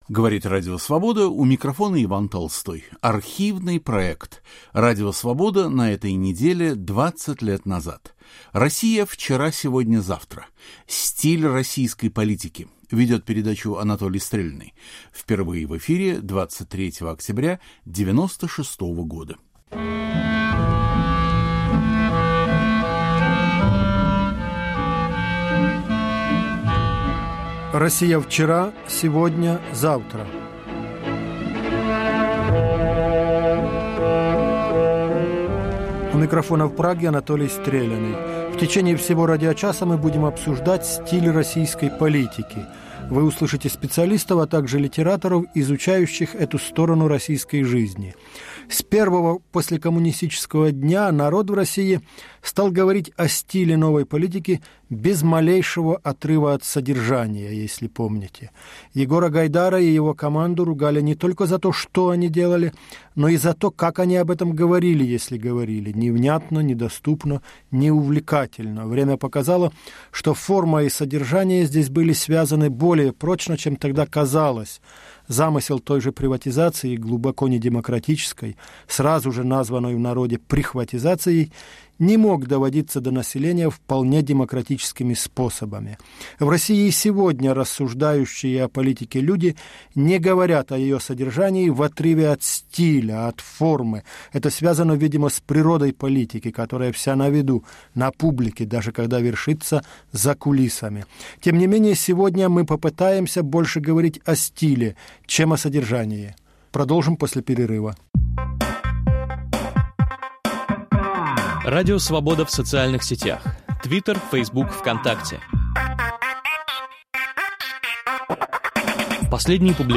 Ведет программу Анатолий Стреляный.